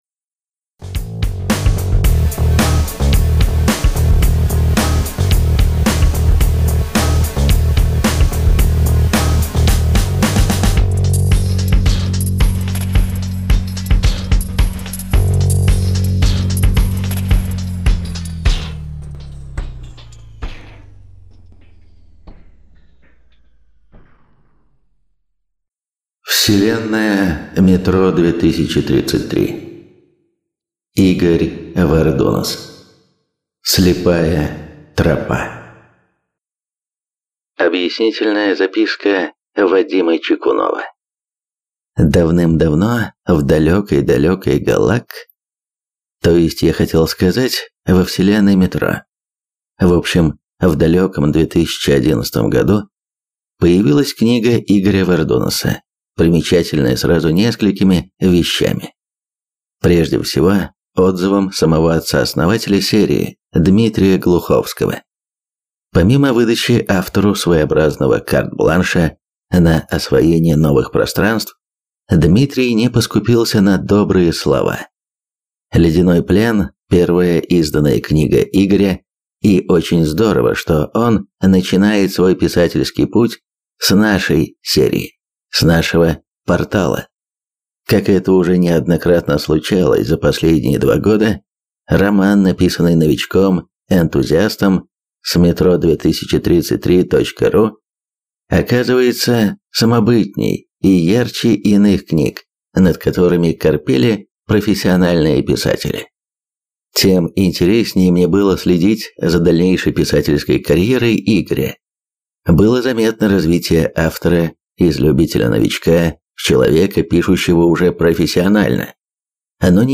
Аудиокнига Метро 2033: Слепая тропа | Библиотека аудиокниг